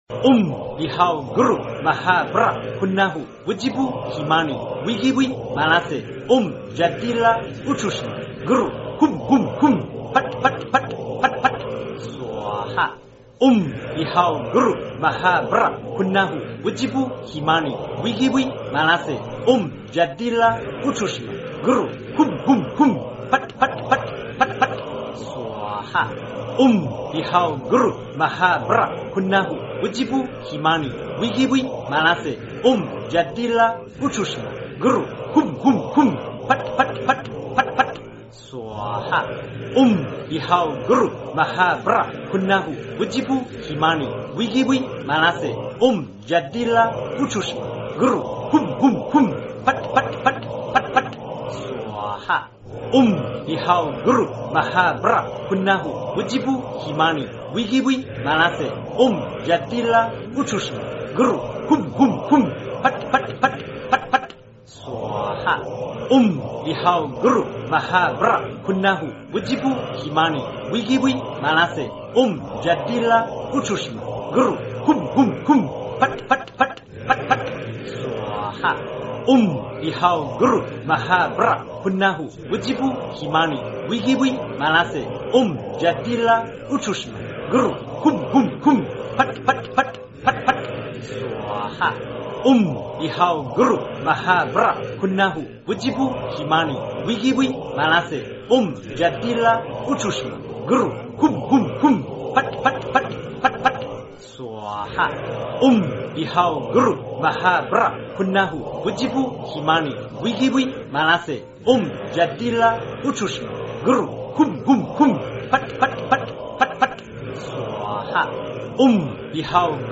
真言